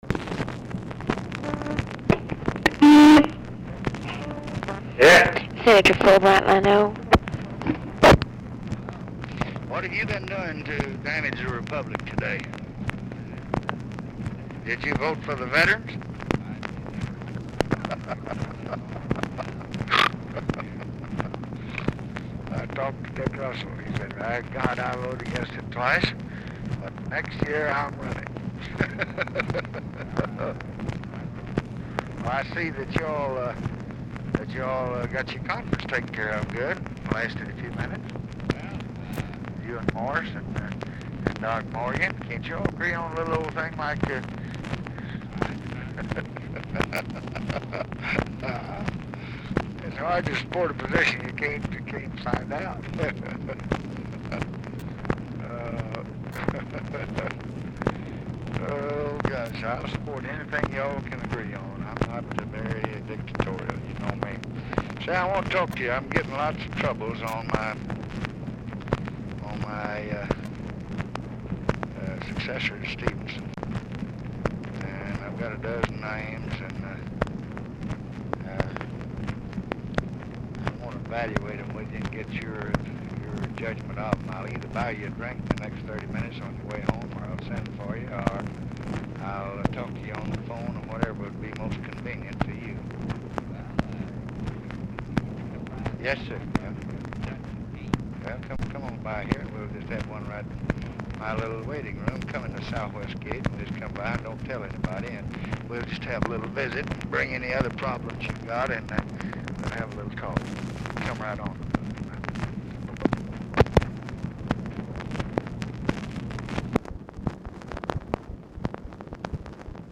Telephone conversation # 8353, sound recording, LBJ and WILLIAM FULBRIGHT, 7/19/1965, 6:34PM
POOR SOUND QUALITY; FULBRIGHT IS ALMOST INAUDIBLE
Format Dictation belt